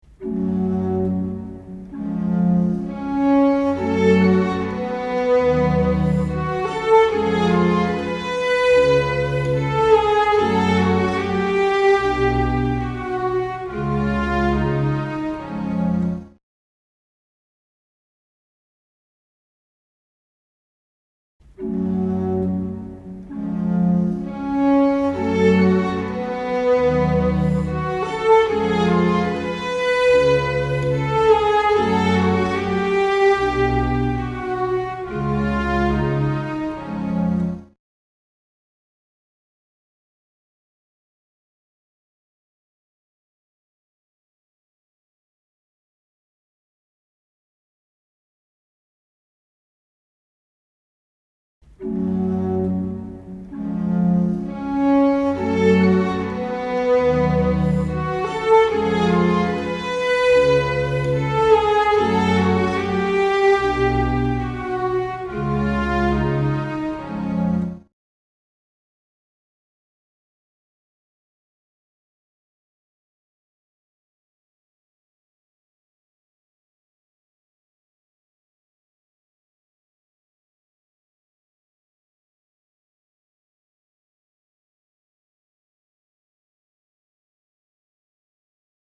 Du får höra varje exempel tre gånger efter varandra med allt längre tid emellan.